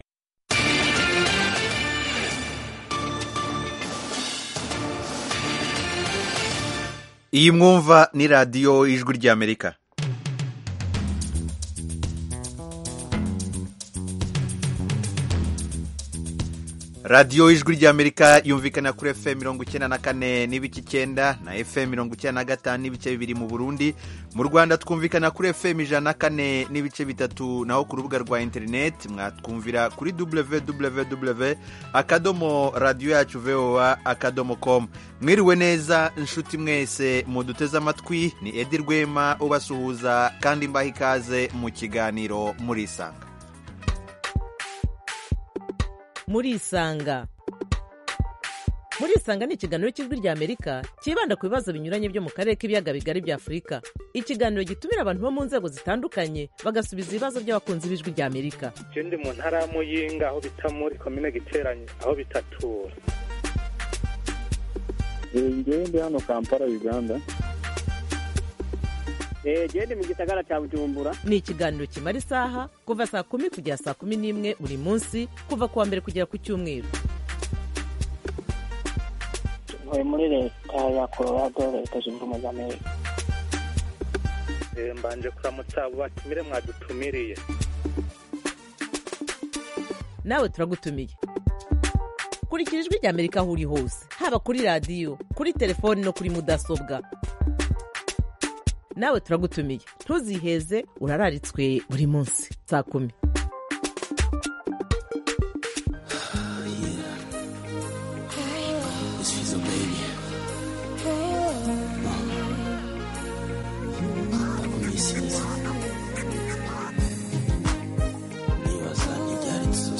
Murisanga itumira umutumirwa, cyangwa abatumirwa kugirango baganira n'abakunzi ba Radiyo Ijwi ry'Amerika. Aha duha ijambo abantu bivufa kuganira n'abatumirwa bacu, batanga ibisobanuro ku bibazo binyuranye bireba ubuzima b'abantu.